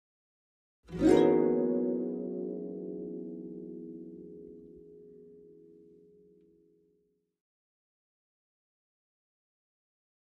Harp, Fast And Short Gliss, Type 2 - High